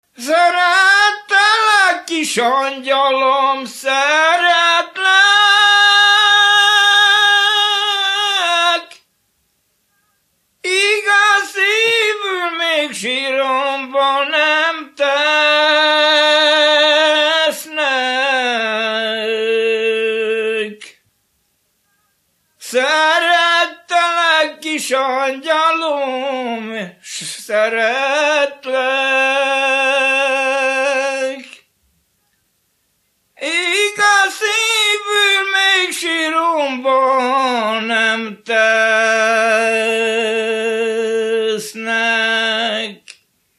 Erdély - Szolnok-Doboka vm. - Szék
ének
Műfaj: Keserves
Pszalmodizáló stílusú dallamok